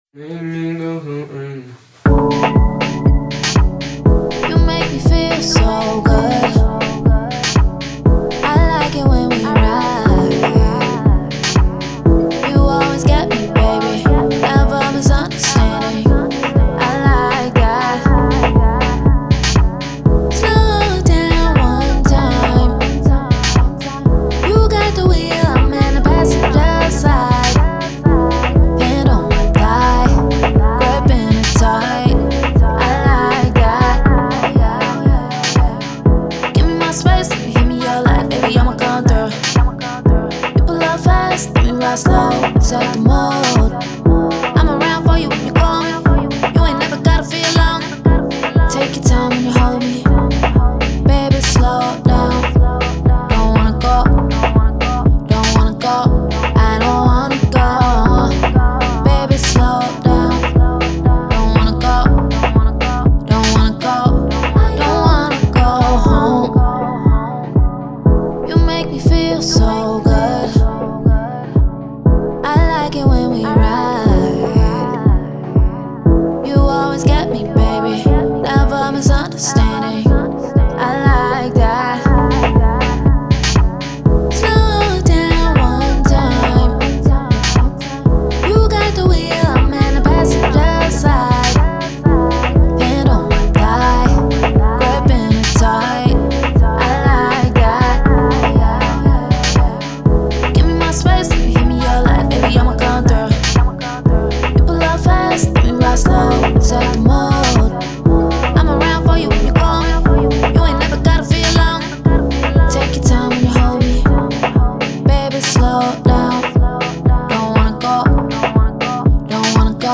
Afrobeat, R&B
F# Minor